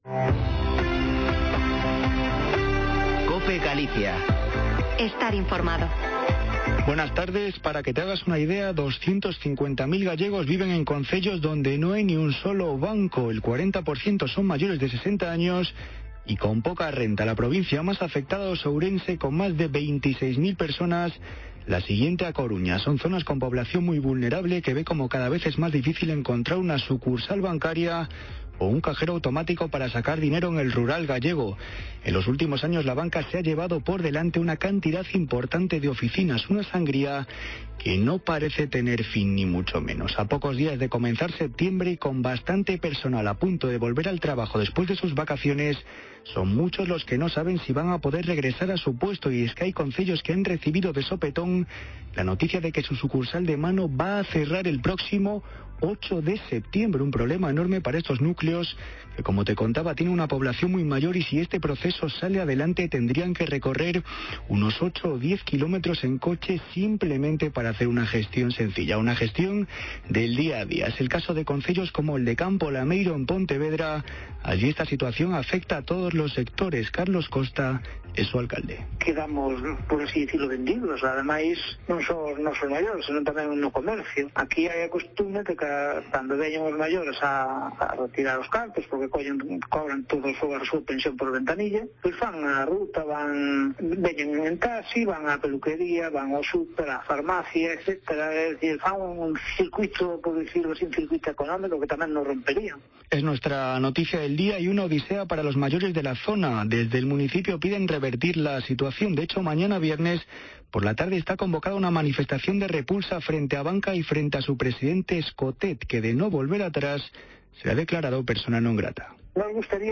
Informativo Mediodía en Cope Galicia 26/08/2021. De 14.48 a 14.58h